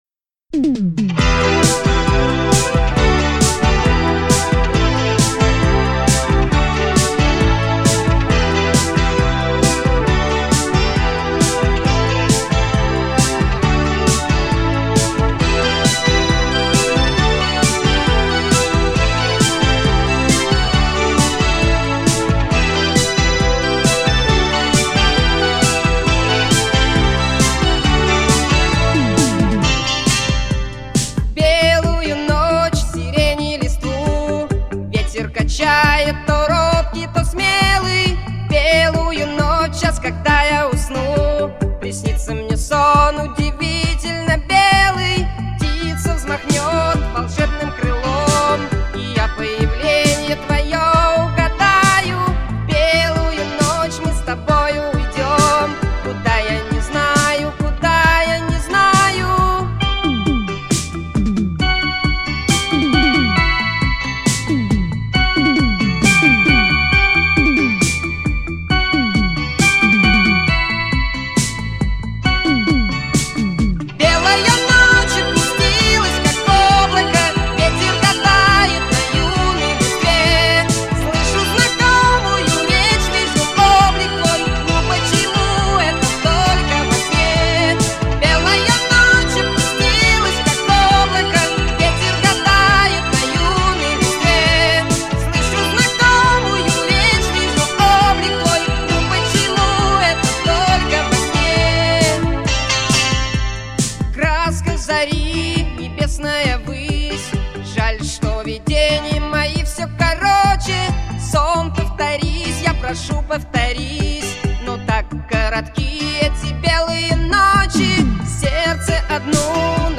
это песня в жанре поп-рок